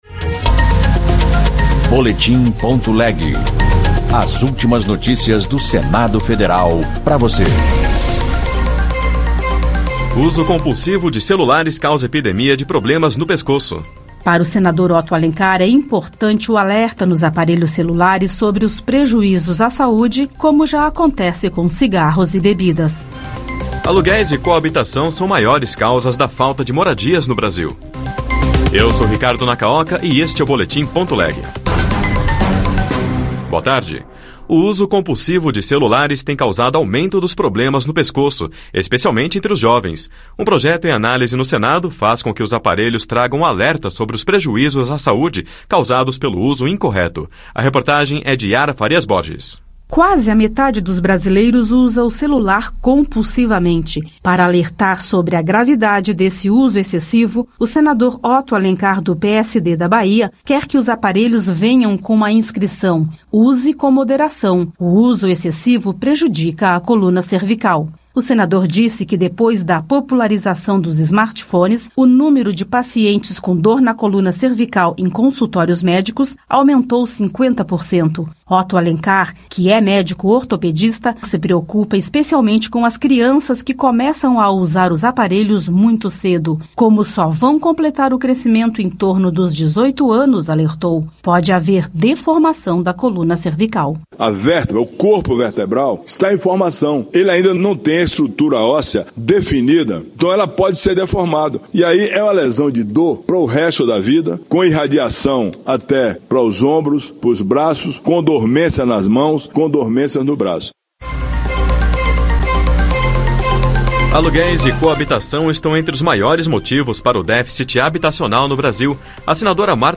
Notícias do Senado Federal em duas edições diárias